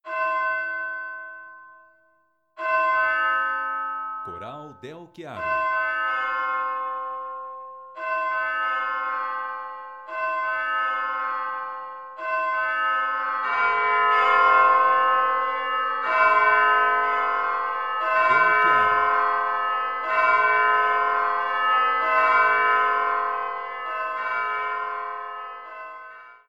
músicas para casamentos